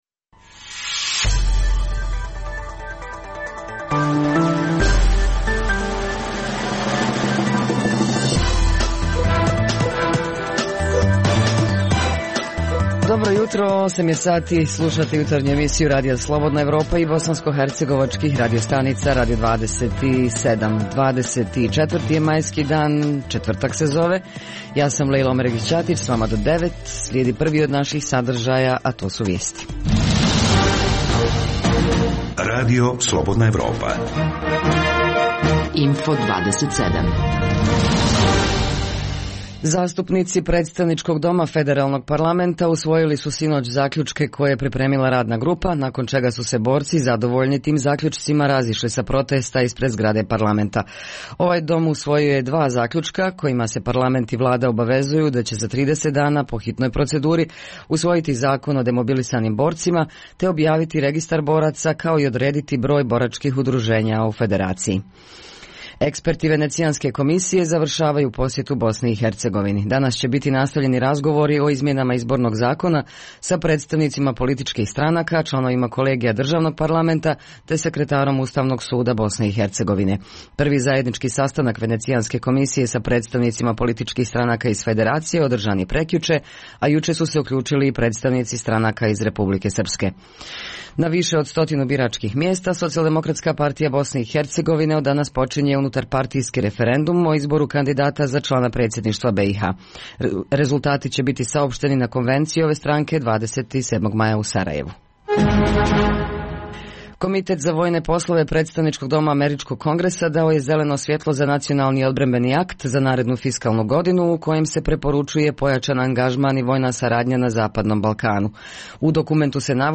U Behalend - tematskom parku ovog jutra ćemo o kulturi komšijskih odnosa. Zanimljivo je čuti šta su, našim dopisnicima, rekli građani Doboja, Banja Luke, Mostara i Travnika.